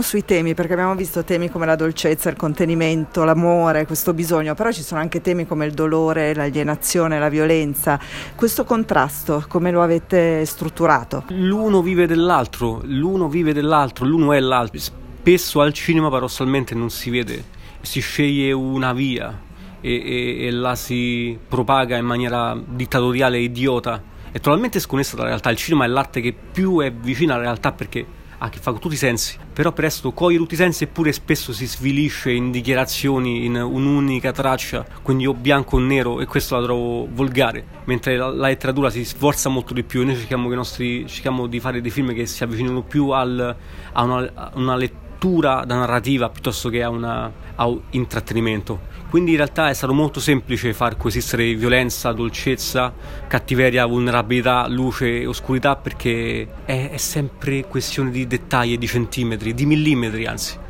È un film che esplora molti temi contrastanti, come se fosse un thriller psicologico. Sentiamo i Fratelli D’Innocenzo